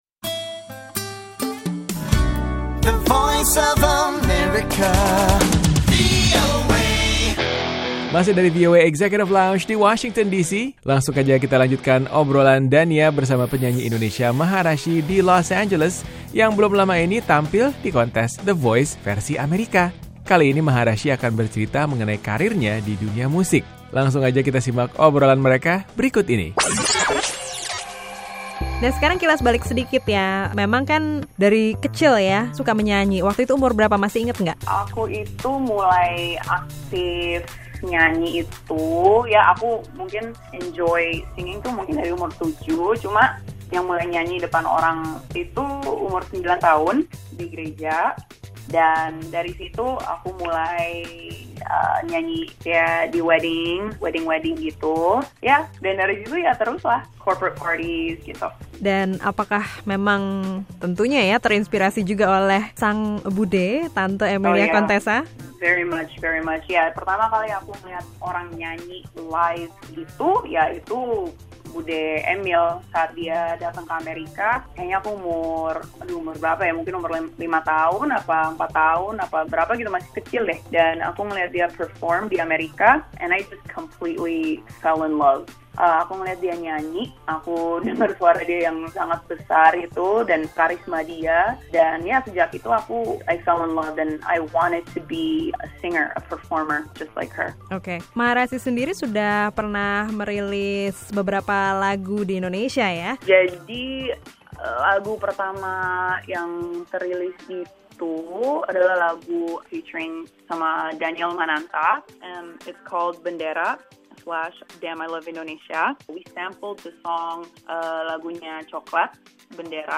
Simak kelanjutan obrolan dengan penyanyi Indonesia